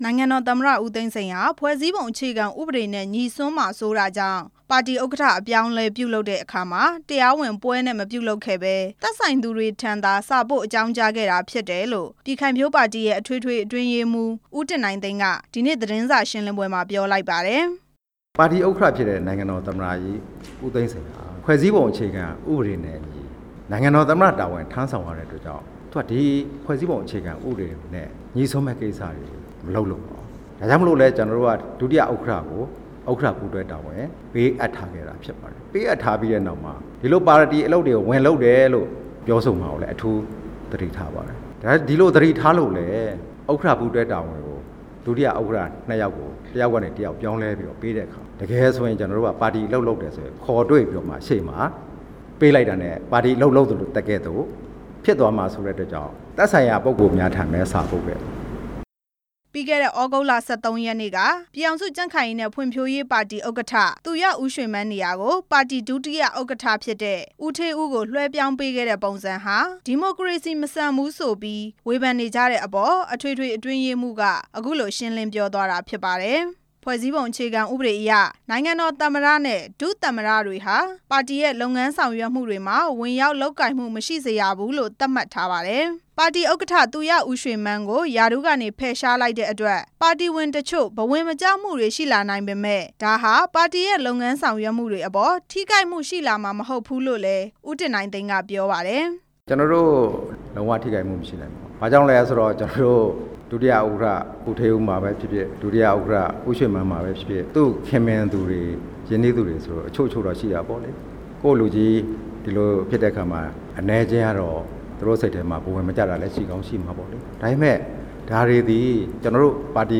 ပြည်ခိုင်ဖြိုးပါတီရဲ့ ခေါင်းဆောင် အပြောင်းအလဲအပေါ် သတင်းစာရှင်းလင်းပွဲ